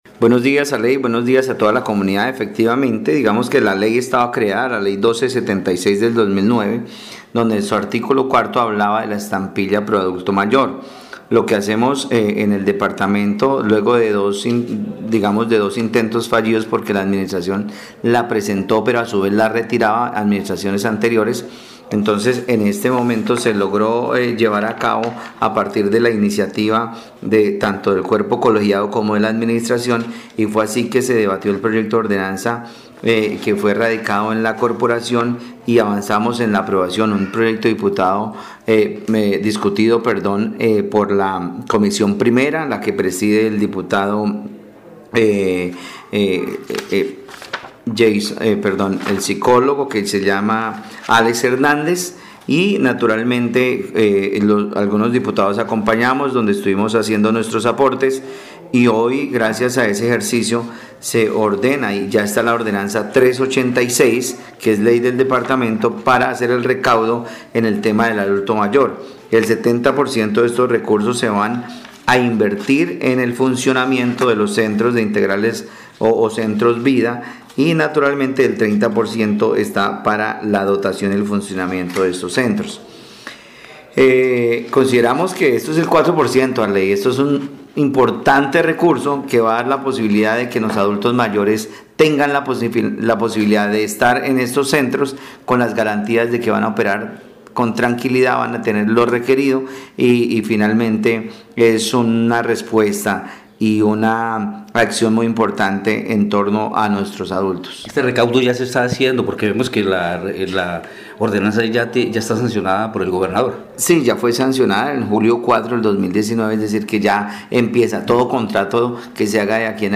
Escuche a Alexander Quevedo, diputado del Guaviare.